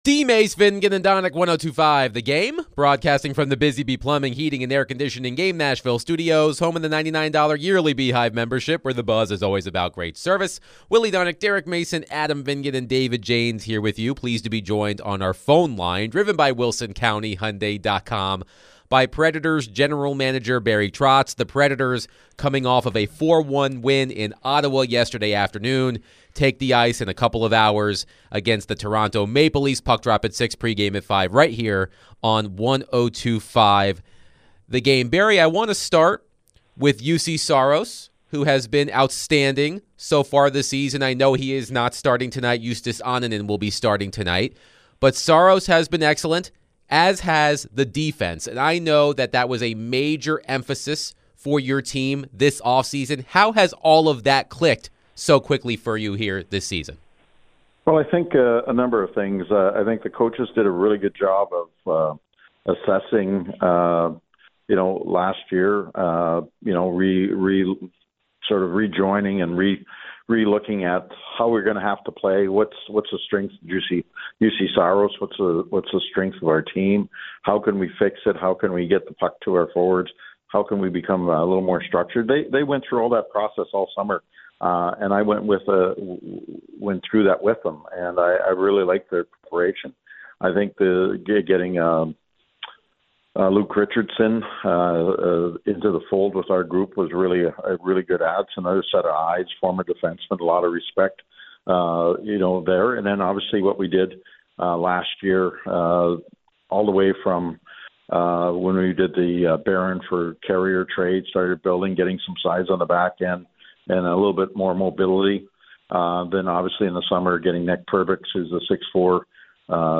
Nashville Predators General Manager Barry Trotz joined DVD after the Preds started 2-0-1, Brady Martin, and more